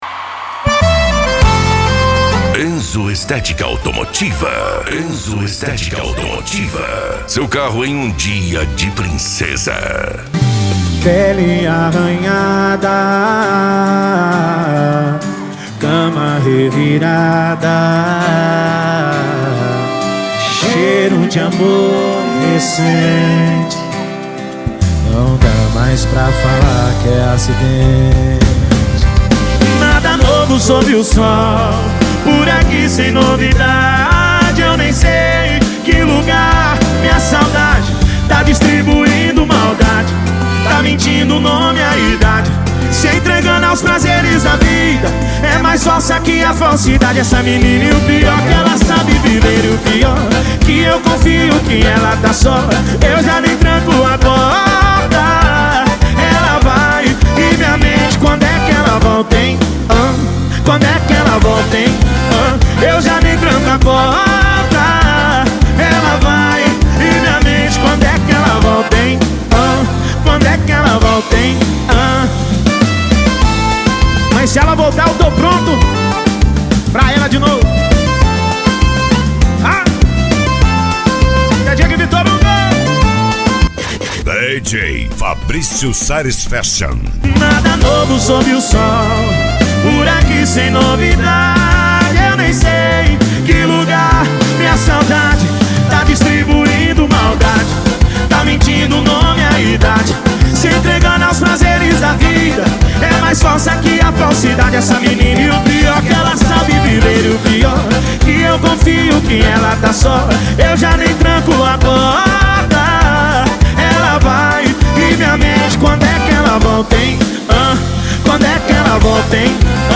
SERTANEJO
Sertanejo Raiz